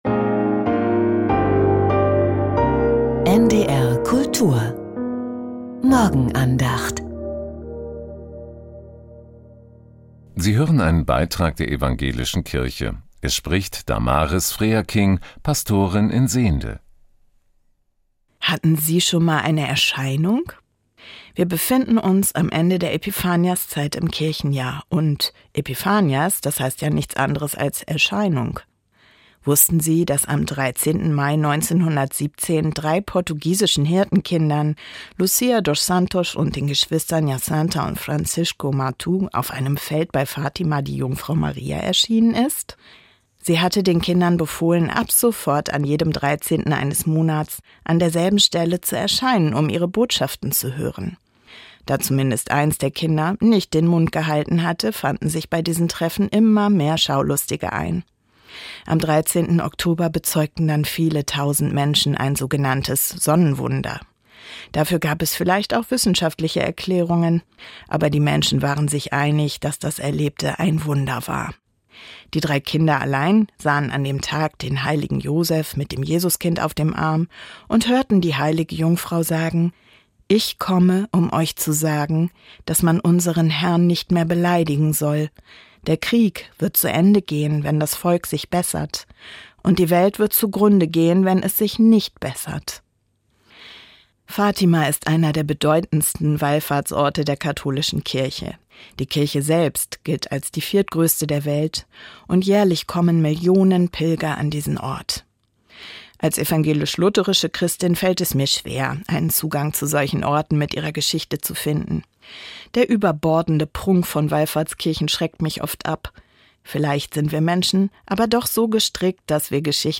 Erscheinung ~ Die Morgenandacht bei NDR Kultur Podcast